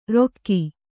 ♪ rokki